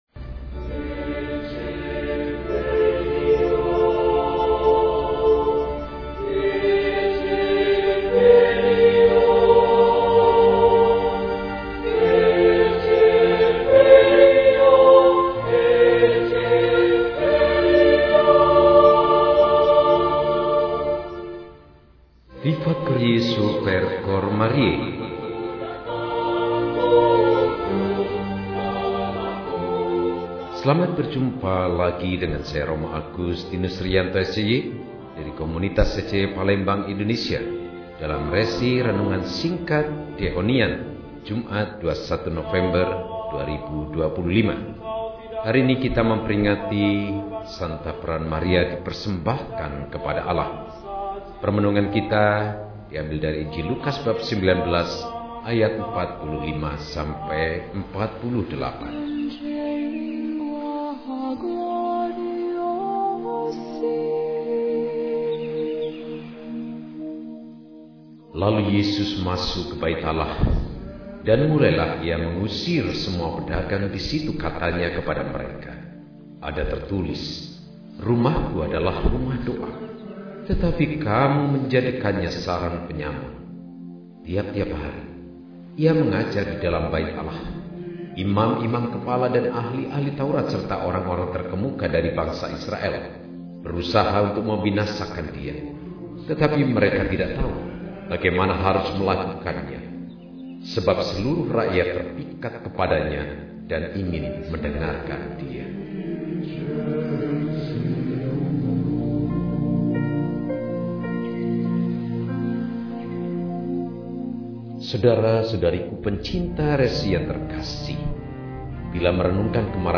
Maria Dipersembahkan kepada Allah – RESI (Renungan Singkat) DEHONIAN